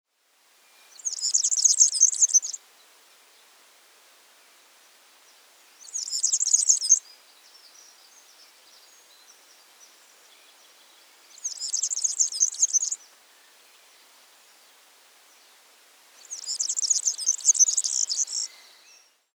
klirrendes, sirrendes Zwitschern kurz (?/i)
lang (?/i) Girlitz
Serinus_serinus_TSA-medium.mp3